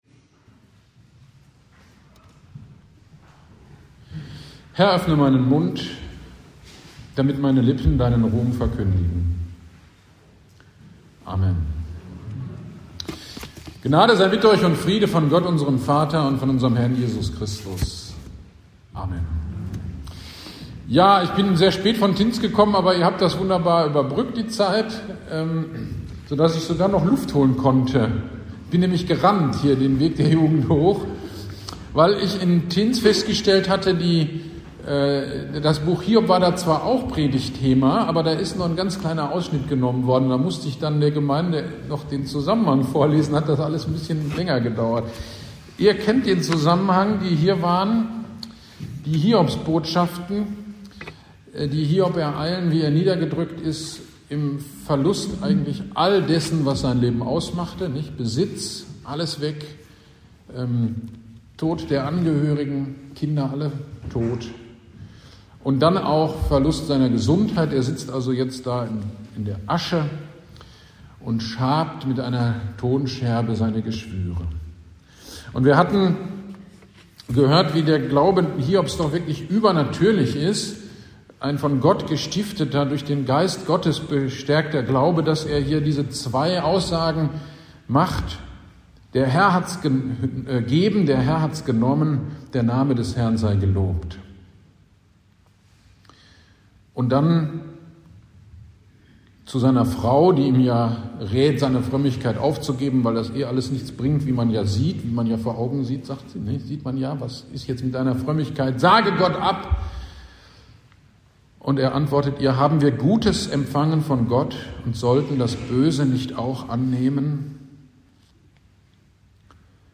Gottesdienst am 12.03.23 Predigt zu Hiob 3-31 - Kirchgemeinde Pölzig